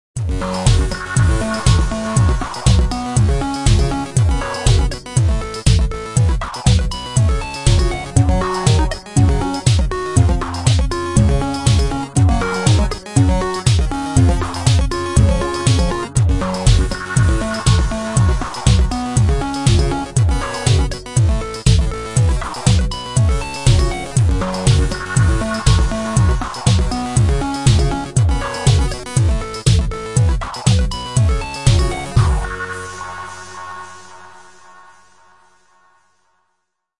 电话 " 7
描述：手机上的7的声音
标签： 嘟嘟声 电话 语气
声道立体声